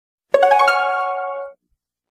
Category: Video Game Ringtones